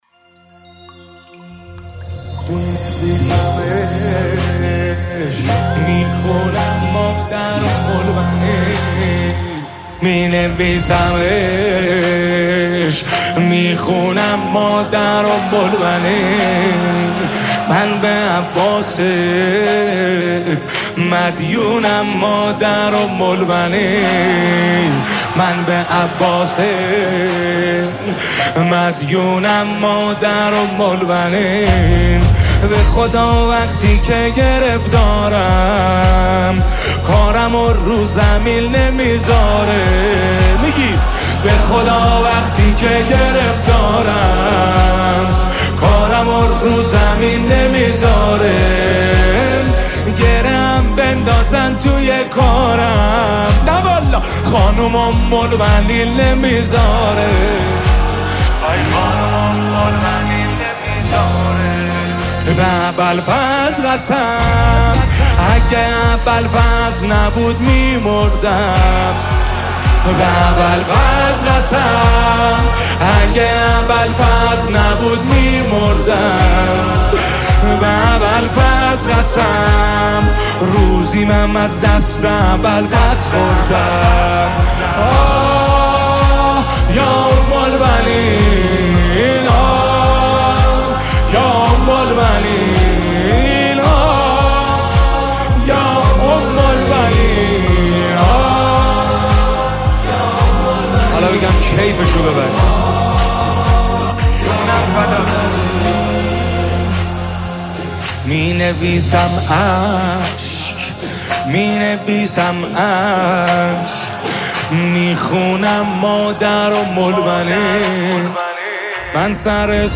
نماهنگ مهدوی دلنشین